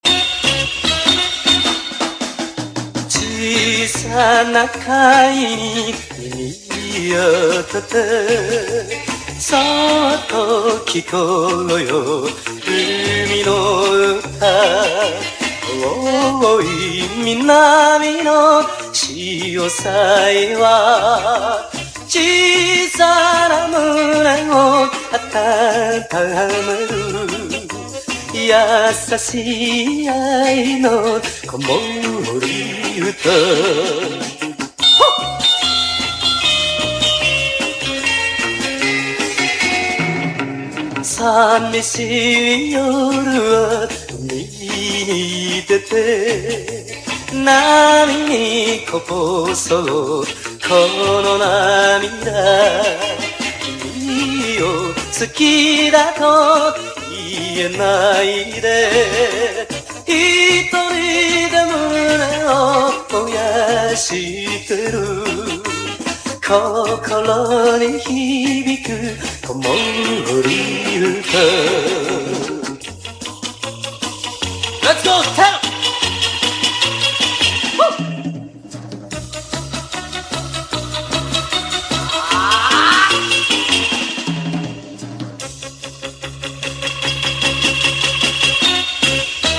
JAPANESE RARE GROOVE
和モノ・インスト歌謡ファンクを多数収録！
ファズ・ギターが炸裂する
グルーヴィーなリズムの